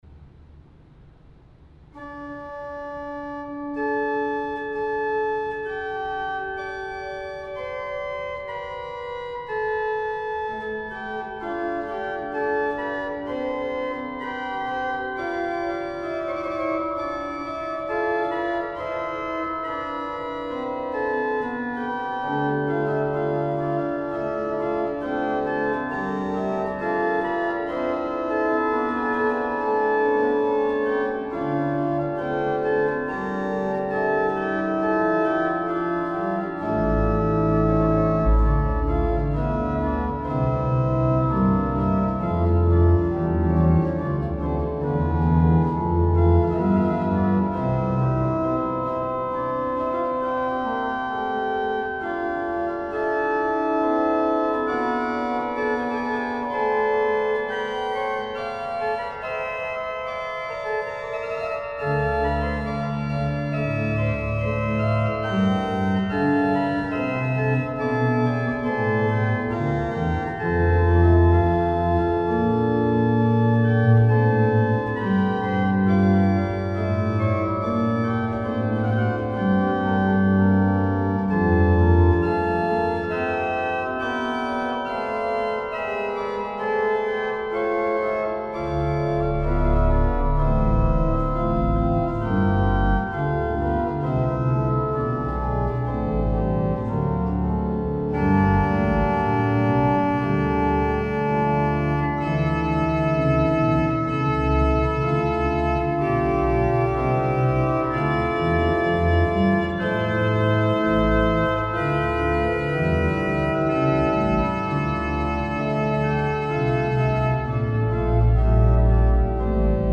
The procedure is familiar from several other pieces in this manuscript: first a four part fugue on the opening stanze of the choral melody. Then the soprano voice is silent for several bars, after which it reenters to state the full choral melody in long notes. The voice leading is superb, creating a profoundly moving piece.